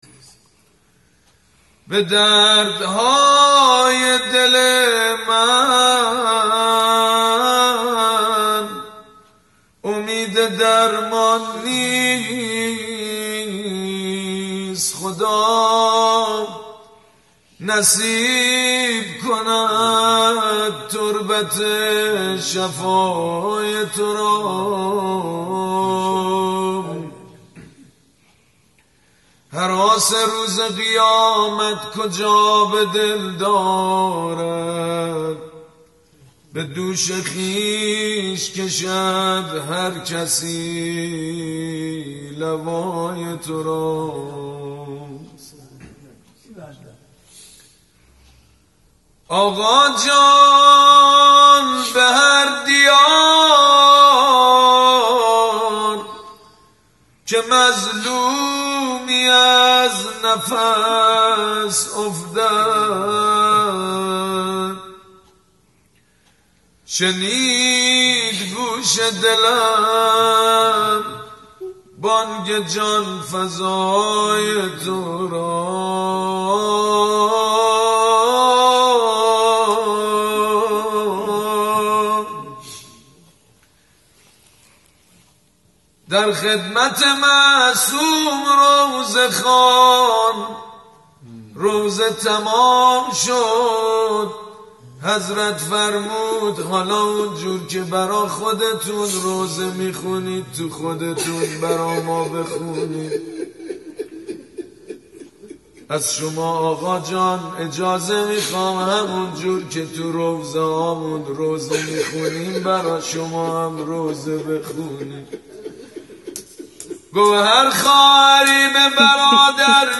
روضه خوانی حاج سید مجید بنی فاطمه | روضه حضرت زینب (س) | بیت آیت الله صافی گلپایگانی